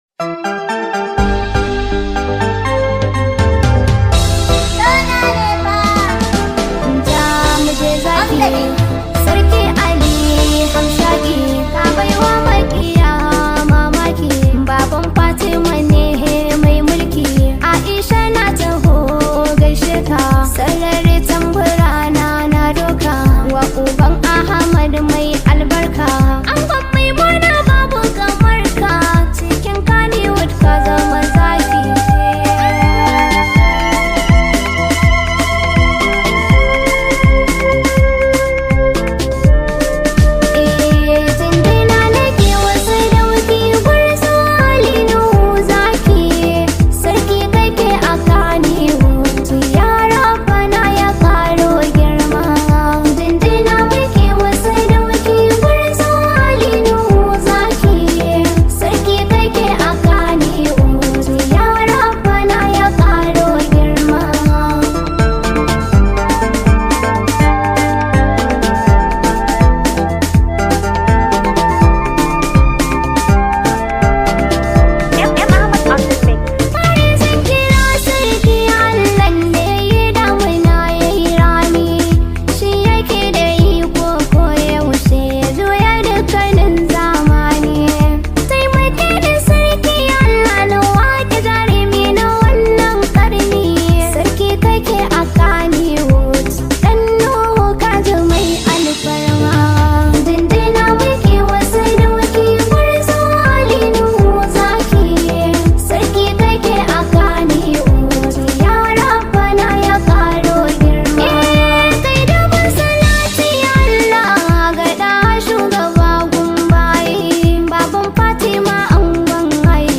high vibe hausa song